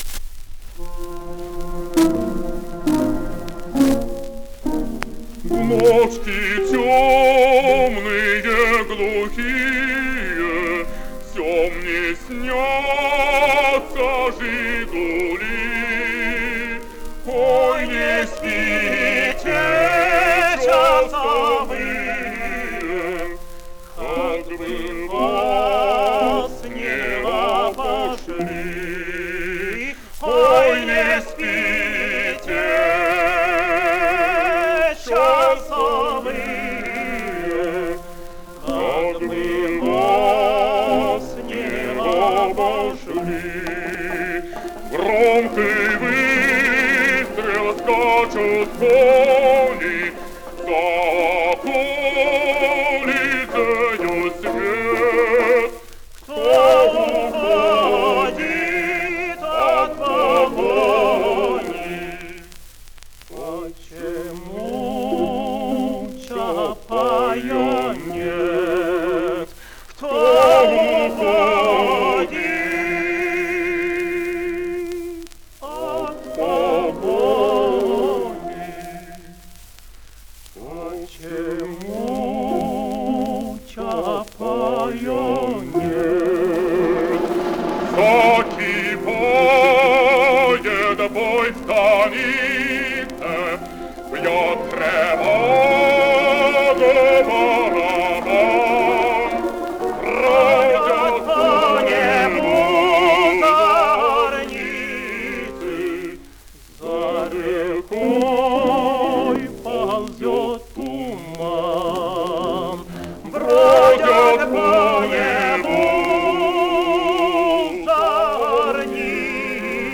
Альтернативное исполнение на мелодию песни